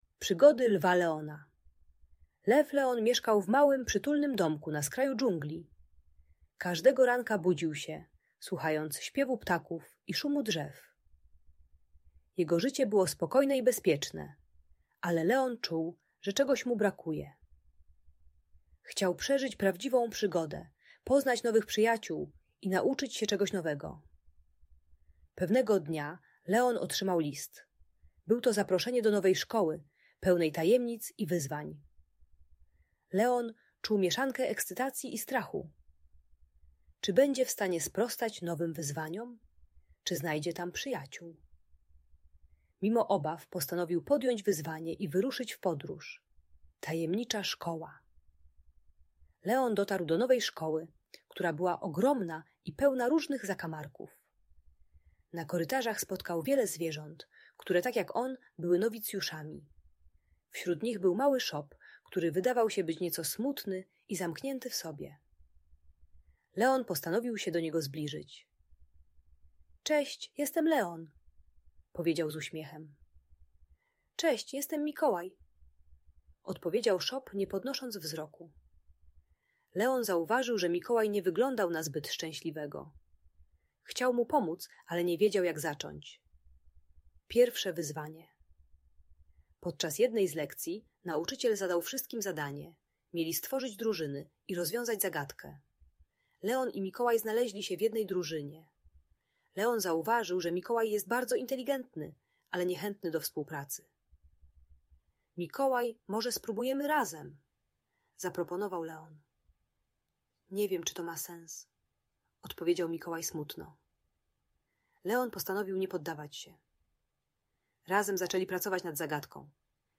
Przygody Lwa Leona - historia pełna przygód i odwagi - Audiobajka